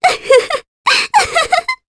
Ophelia-Vox_Happy2_jp.wav